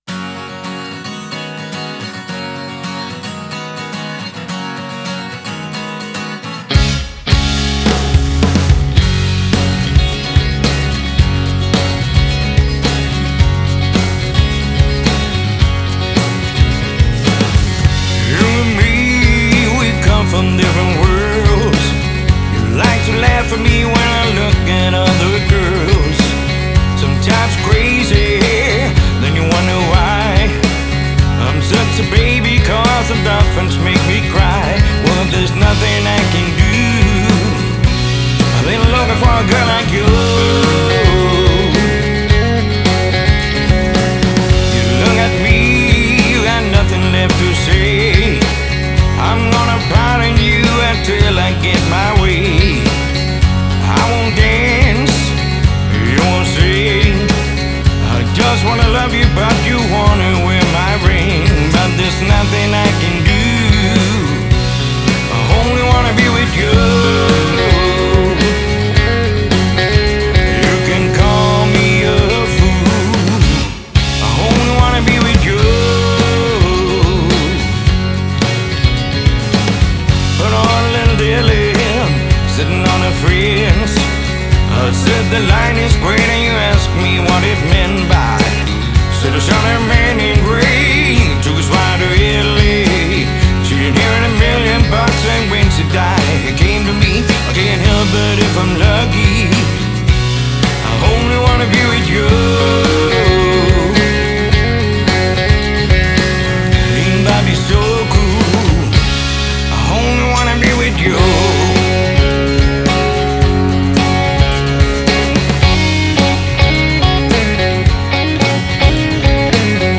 • Coverband
• Dansband